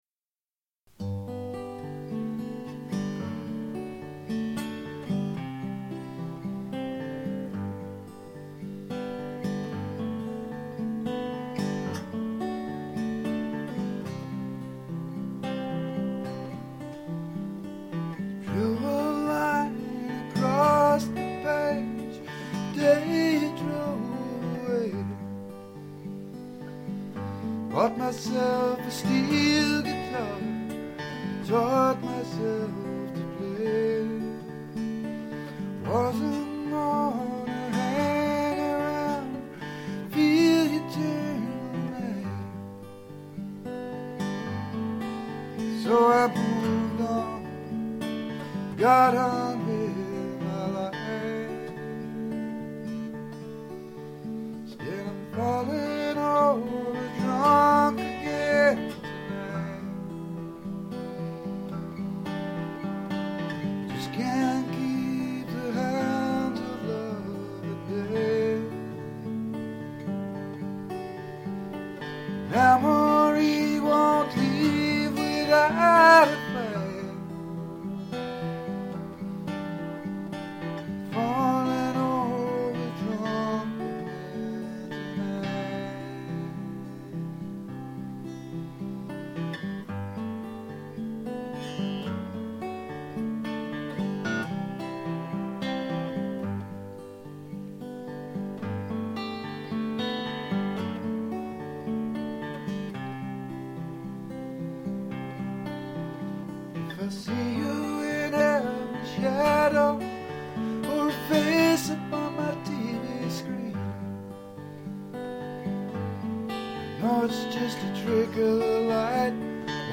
Well, maybe there's five if you count the A7 (sus4).  About developing a positive mental attitude to loss and why it fools no-one, least of all yourself.